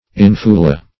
Search Result for " infula" : The Collaborative International Dictionary of English v.0.48: Infula \In"fu*la\, n.; pl.